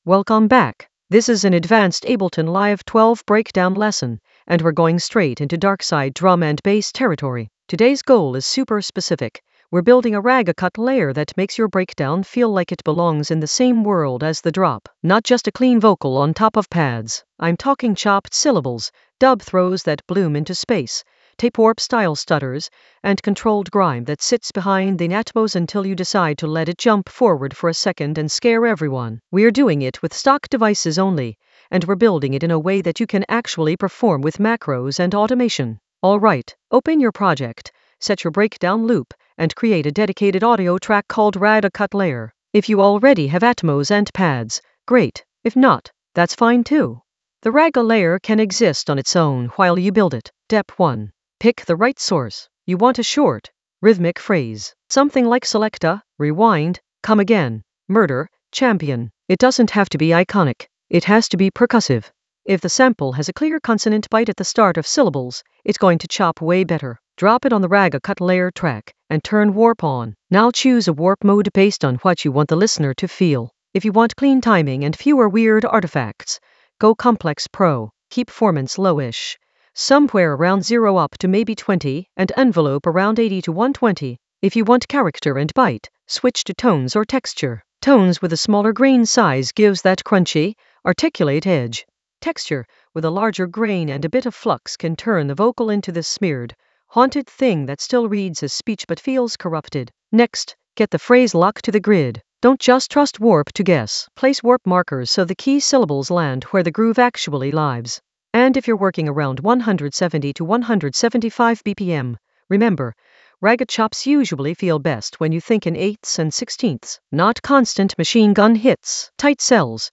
Narrated lesson audio
The voice track includes the tutorial plus extra teacher commentary.
An AI-generated advanced Ableton lesson focused on Darkside breakdown: ragga cut layer in Ableton Live 12 in the FX area of drum and bass production.